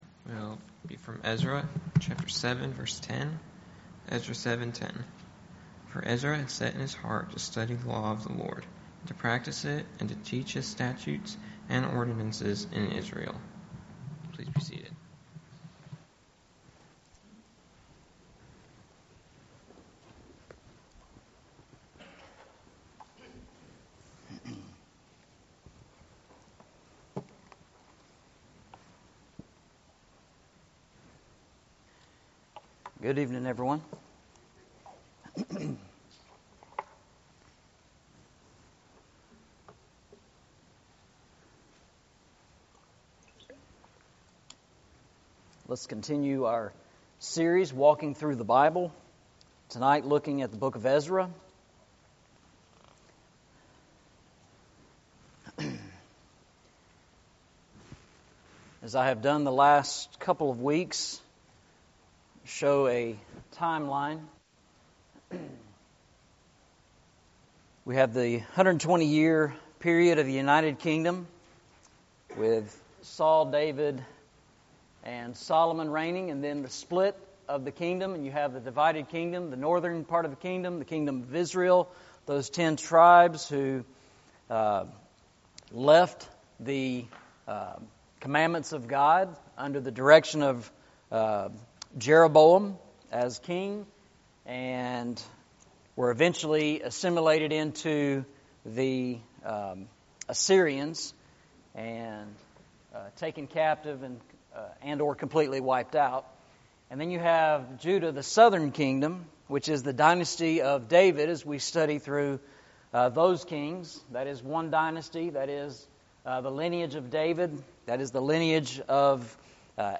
Eastside Sermons
Service Type: Sunday Evening